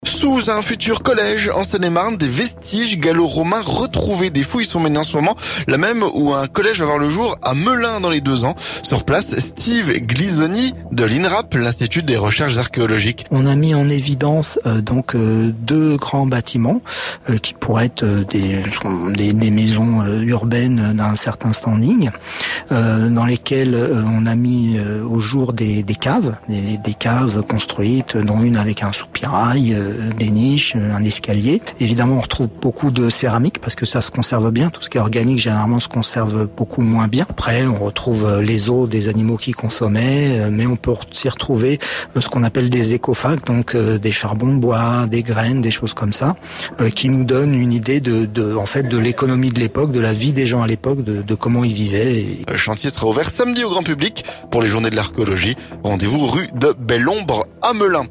ARCHEOLOGIE - Visite du chantier de fouilles sur le futur collège de Melun